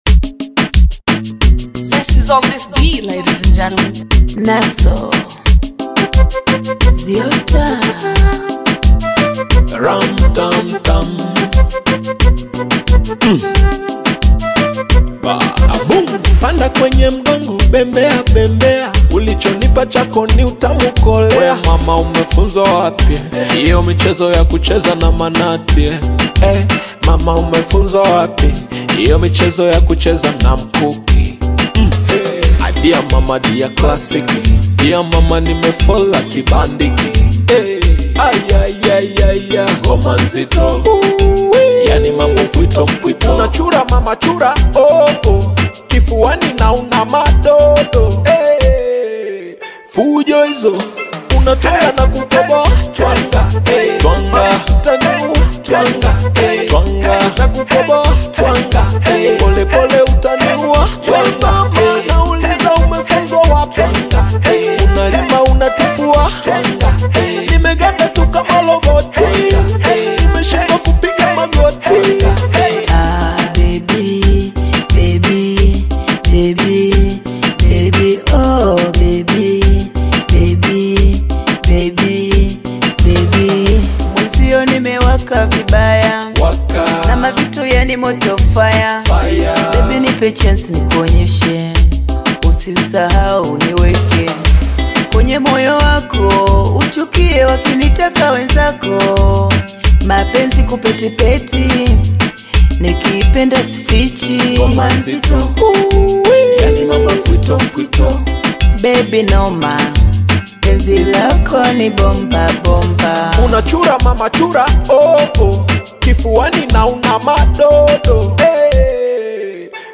vibrant and rhythmic song
a lively and danceable tune